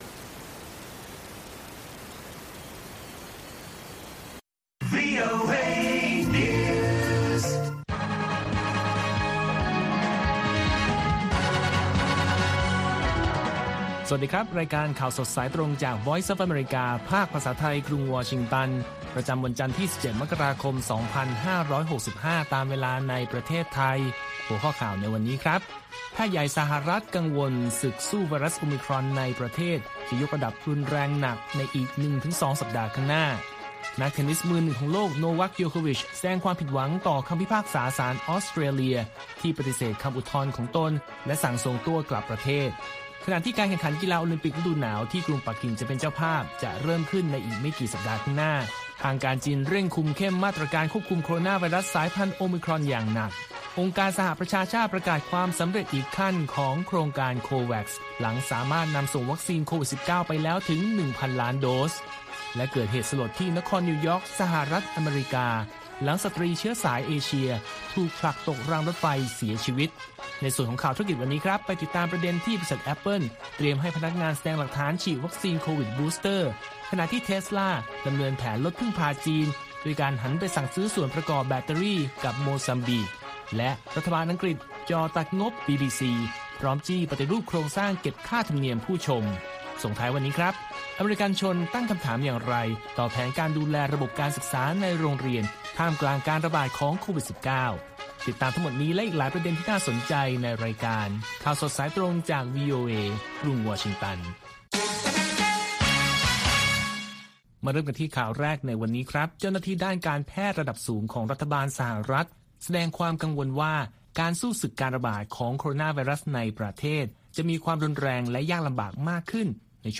ข่าวสดสายตรงจากวีโอเอ ภาคภาษาไทย ประจำวันจันทร์ที่ 17 กันยายน 2565 ตามเวลาประเทศไทย